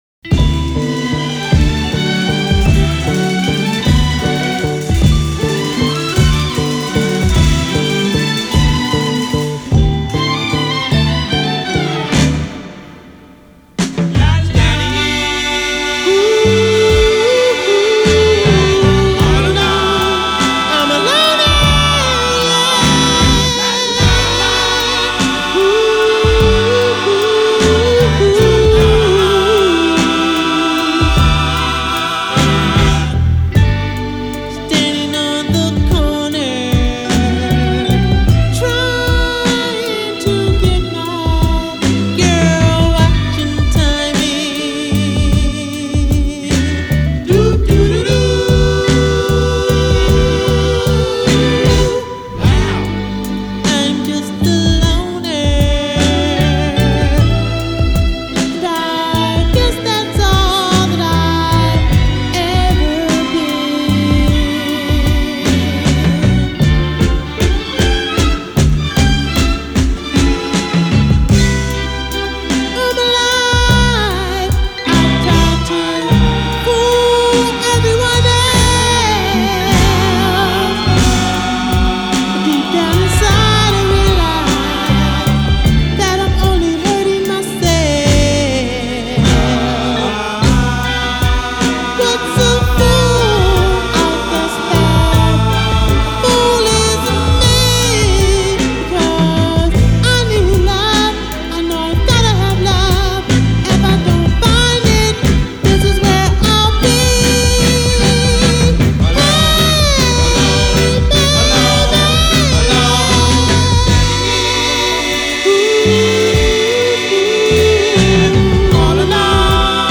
Genero: Blues